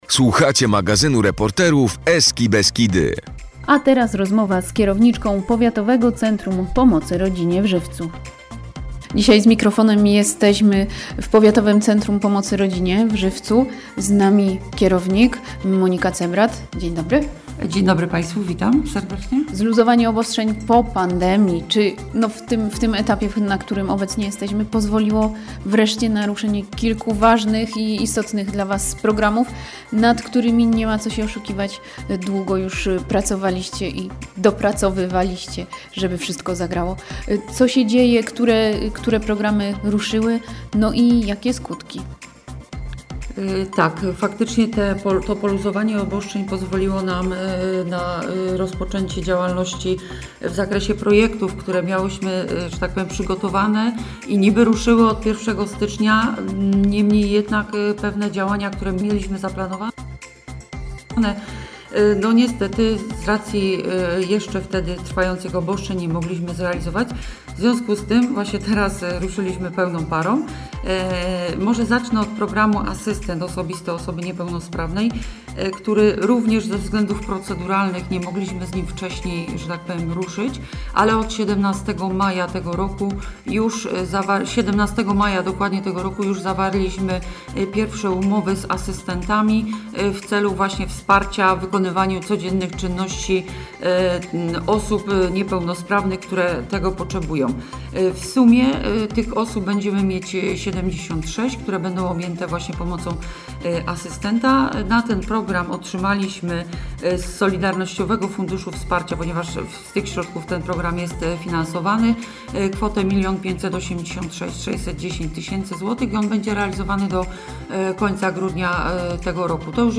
Emisja wywiadu odbyła się o godzinie 22:45 w ramach magazynu – Eska Beskidy News i trwała 13.49 min.
Audycja-w-radio-ESKA.mp3